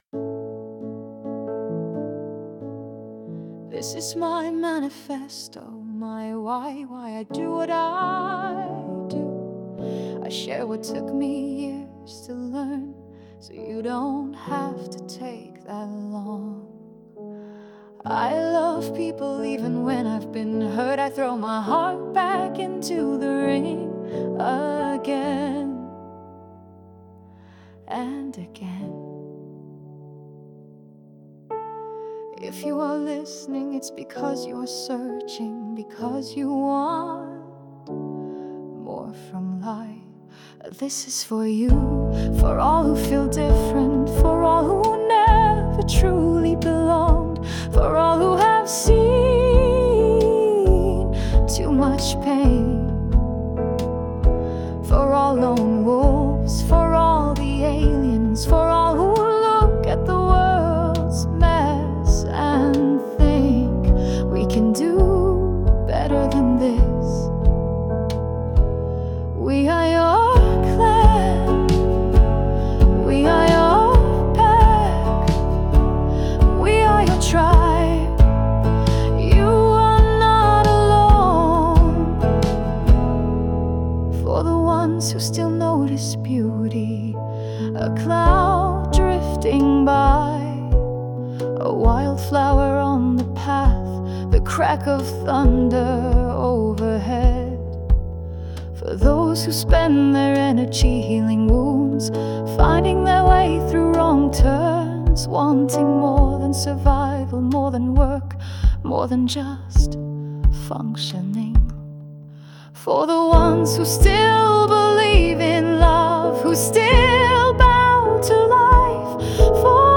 Ich habe dieses Manifest vertont – als Lied.